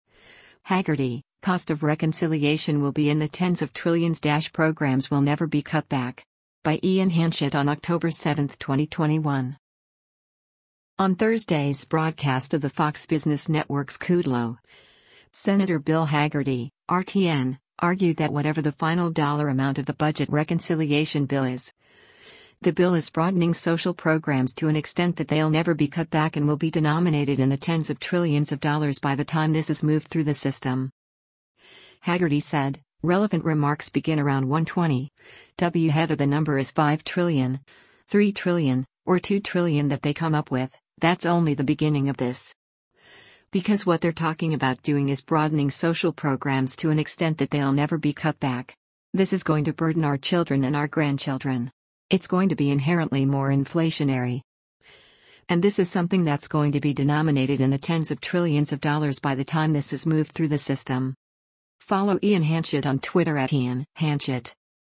On Thursday’s broadcast of the Fox Business Network’s “Kudlow,” Sen. Bill Hagerty (R-TN) argued that whatever the final dollar amount of the budget reconciliation bill is, the bill is “broadening social programs to an extent that they’ll never be cut back” and will “be denominated in the tens of trillions of dollars by the time this is moved through the system.”